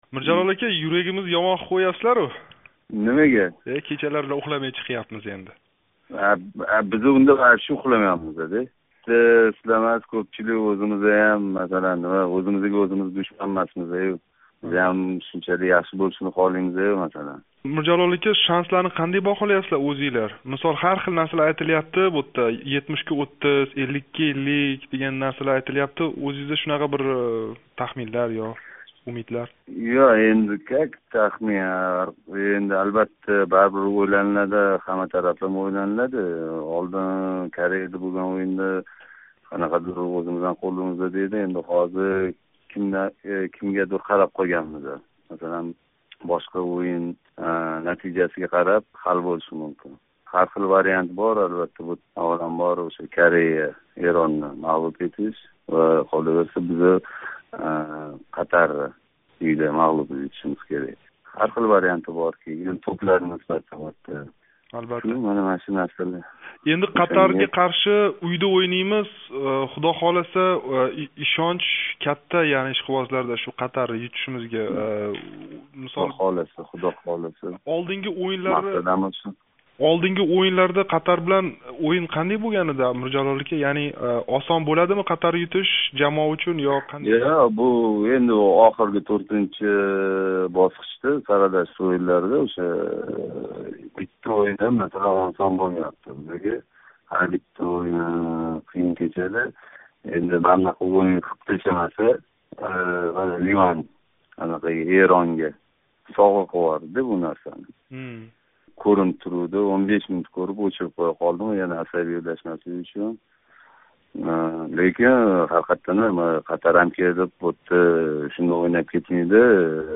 Ўзбекистон-Қатар ўйини олдидан терма жамоа мураббийи Миржалол Қосимов Озодликка эксклюзив интервью бериб, ўйинга тайёргарликлар ҳамда Жанубий Корея билан ўйиндаги автогол муаллифи Акмал Шораҳмедовнинг бугунги аҳволи борасида сўзлади.
Миржалол Қосимов билан суҳбат